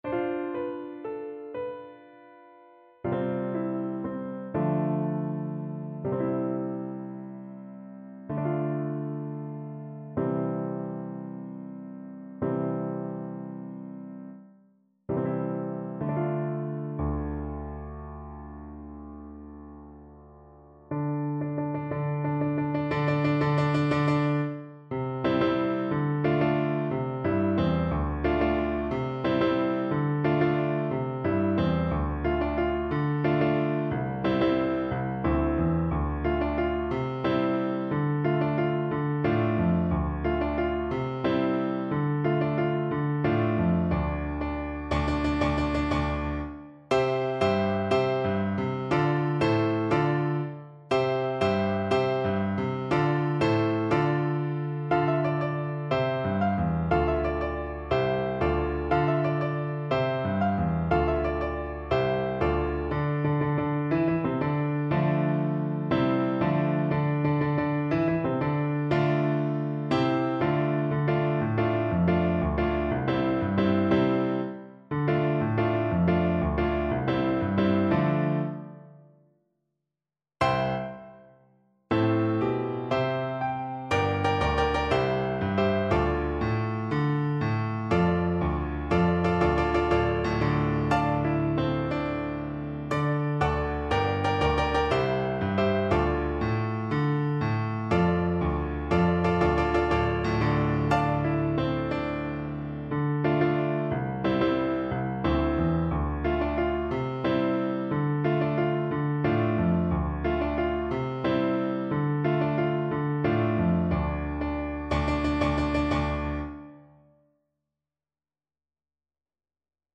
Play (or use space bar on your keyboard) Pause Music Playalong - Piano Accompaniment Playalong Band Accompaniment not yet available transpose reset tempo print settings full screen
Flute
D minor (Sounding Pitch) (View more D minor Music for Flute )
Slow .=c.80
Traditional (View more Traditional Flute Music)